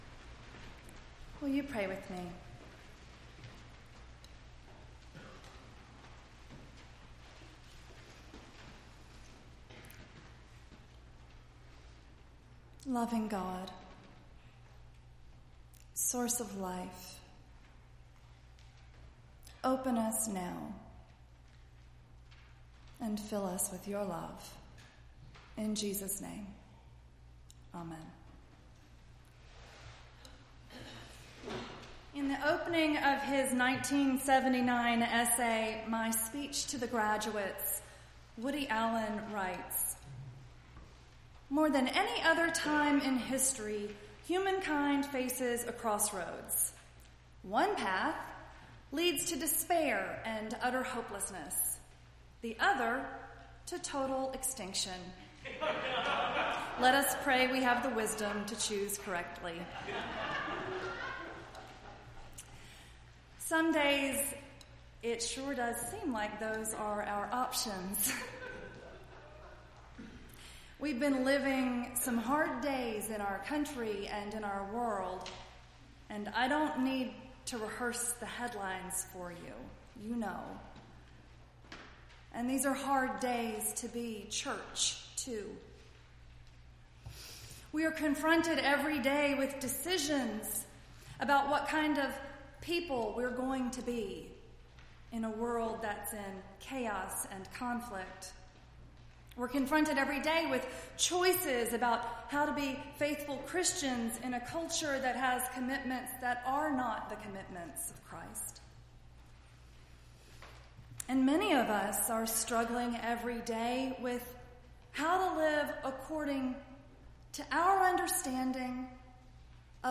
2-12-17-sermon.mp3